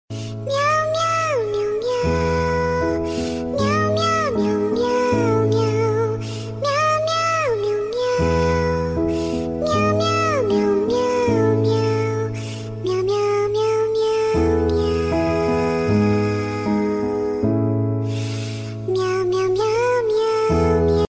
Gato triste Miau miau sound effects free download